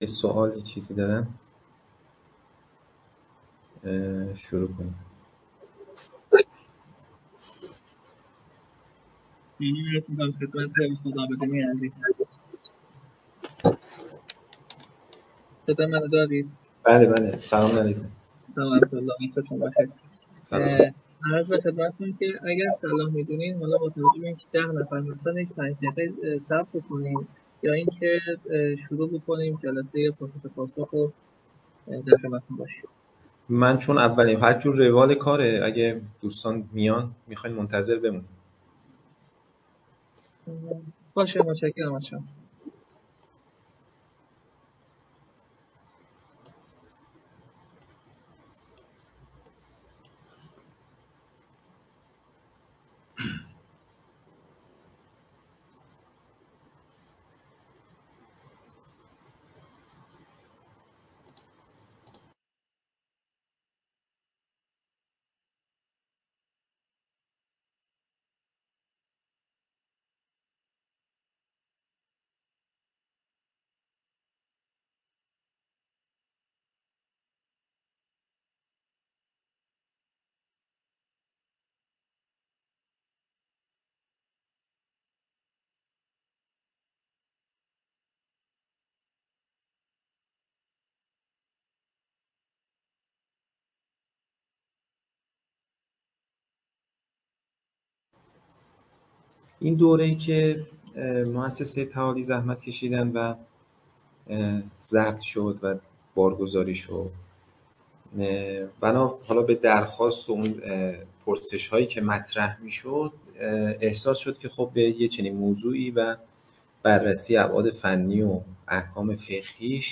اموال دیجیتال (احکام رمزارزها) - جلسه-پرسش-و-پاسخ